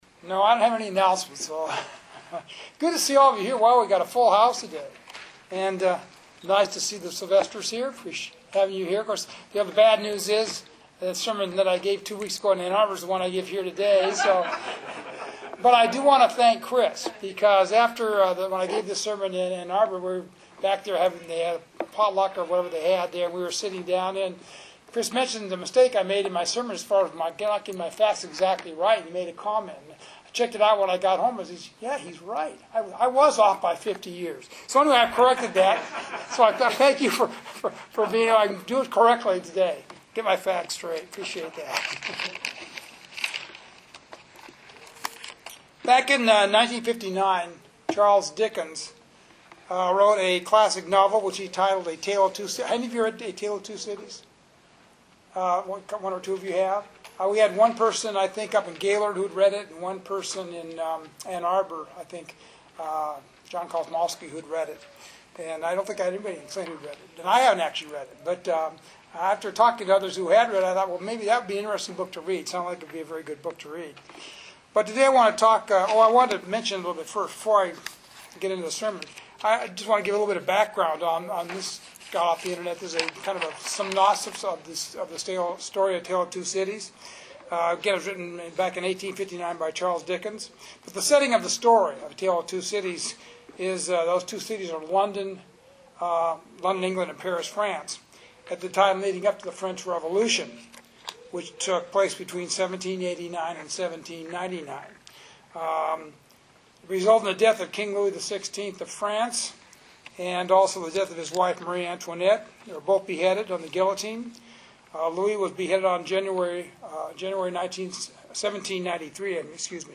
This two-part sermon is to show the tale and history of two other cities.